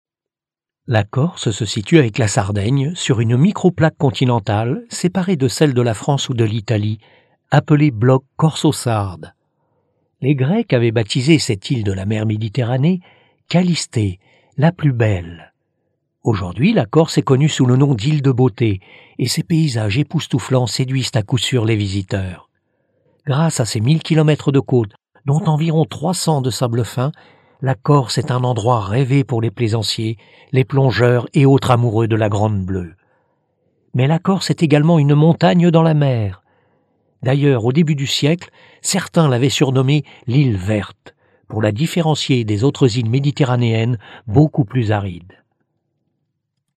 Comédien depuis vingt ans, ma voix au timbre médium sait être selon les exigences,tour à tour,posée,sérieuse,claire, pédagogique,explicative, rassurante mais aussi confidentielle, mystérieuse ou encore joueuse,exubérante, émerveillée...
Sprechprobe: Werbung (Muttersprache):